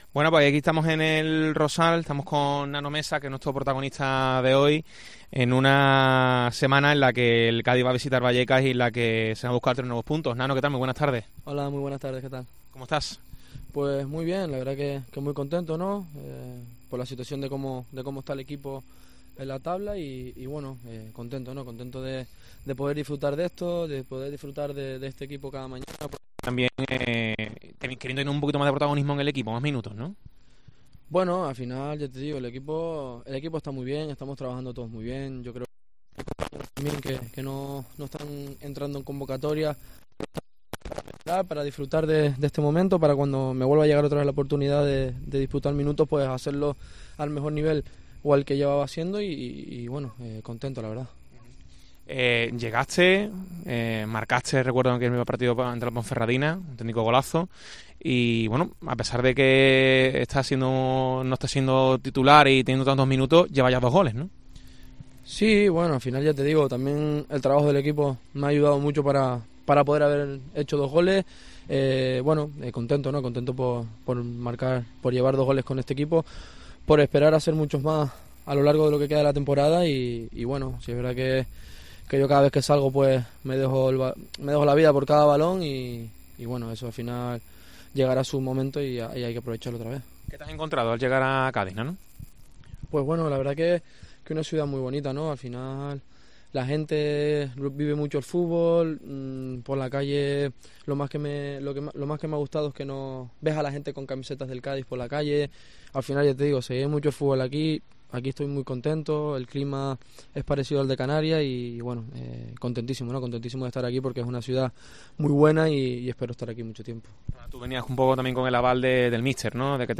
El jugador canario del conjunto cadista habla en los micrófonos de COPE
Entrevista al jugador del Cádiz CF Nano Mesa